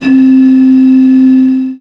55bw-flt13-c4.aif